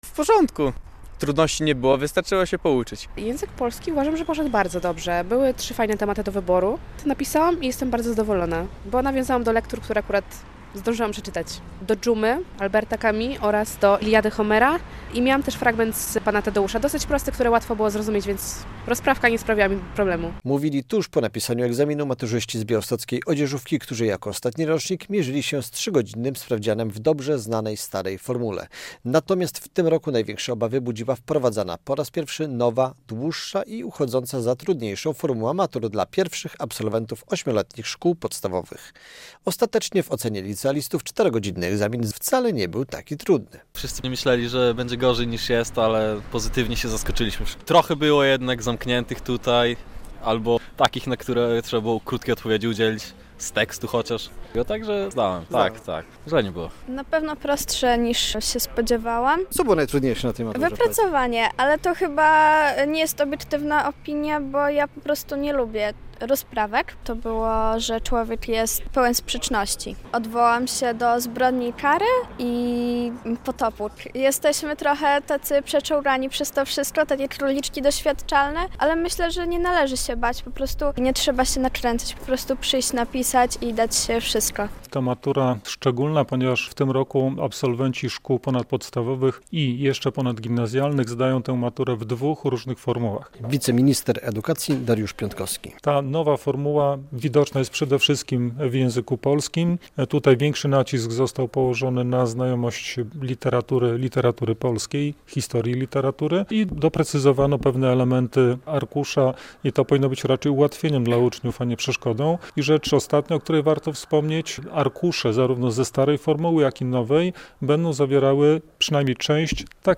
Absolwenci liceów i techników napisali maturę z języka polskiego - relacja